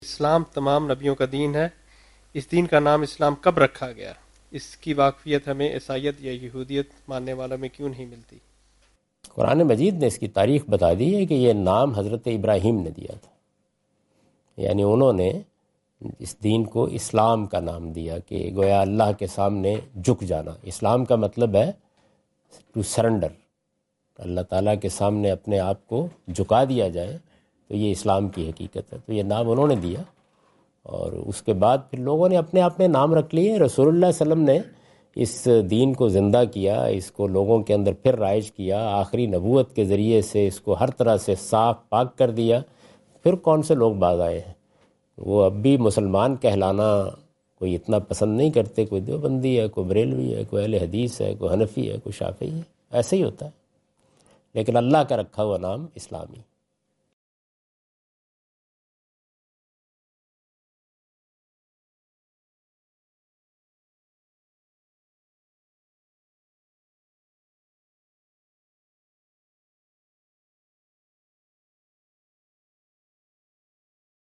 Javed Ahmad Ghamidi answer the question about "When was the Religion Named Islam?" during his Australia visit on 11th October 2015.
جاوید احمد غامدی اپنے دورہ آسٹریلیا کے دوران ایڈیلیڈ میں "دین کا نام اسلام کب رکھا گیا؟" سے متعلق ایک سوال کا جواب دے رہے ہیں۔